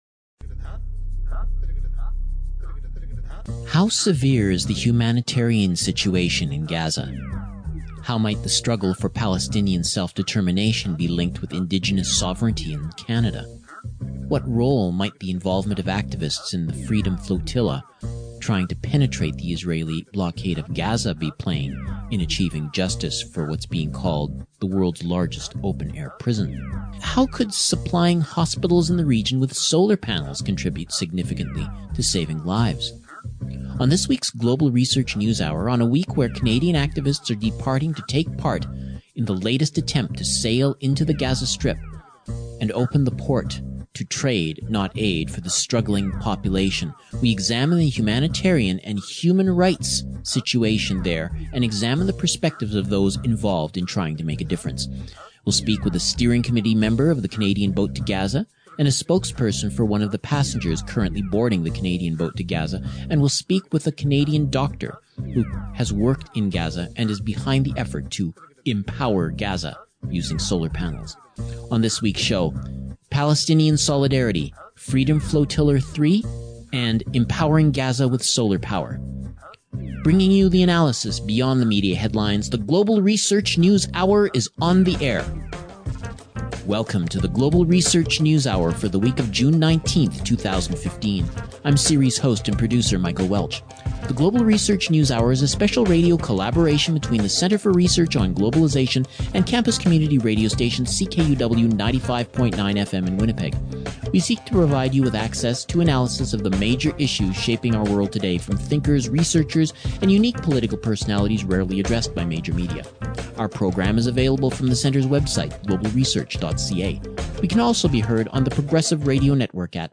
Interviews with activists Canadian activists on efforts to assist struggling Gazans